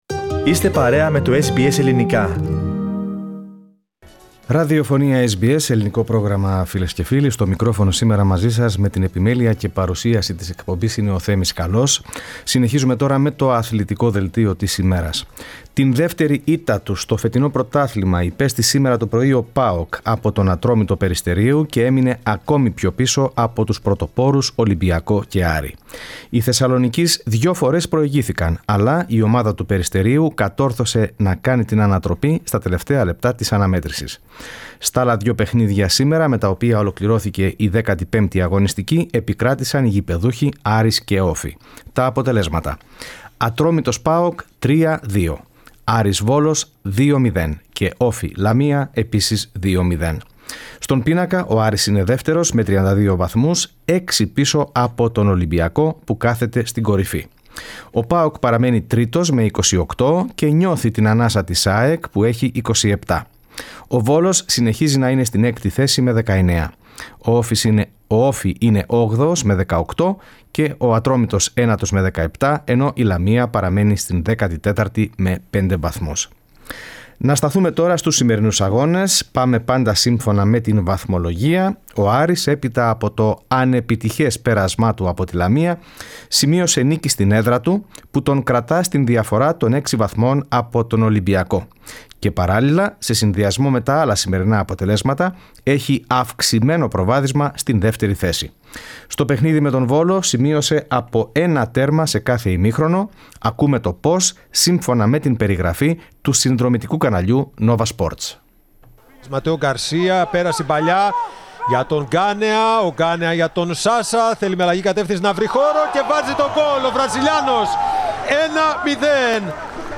Αθλητικό δελτίο: Το καθήκον του ο Άρης, ήττα για ΠΑΟΚ από Ατρόμητο